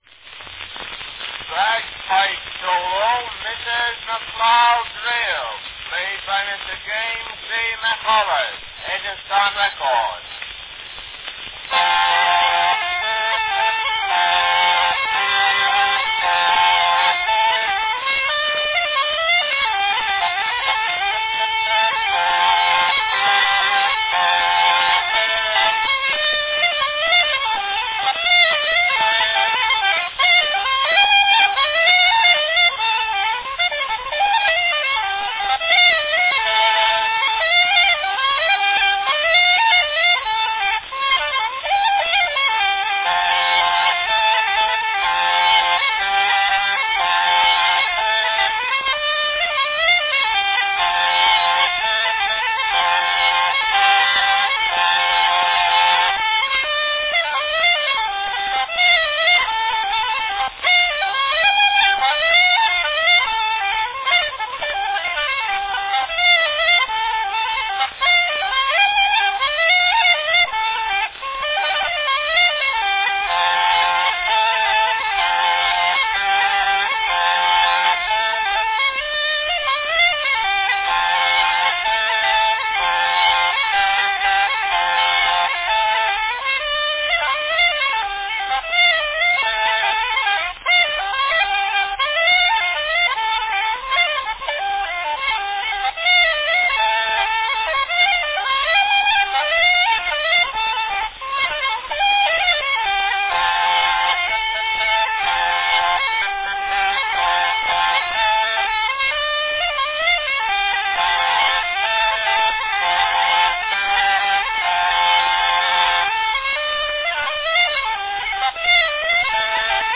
One of the earliest commercial recordings of the bagpipe
Category Bagpipe solo
Bagpipe recordings on wax cylinder are uncommon
the uilleann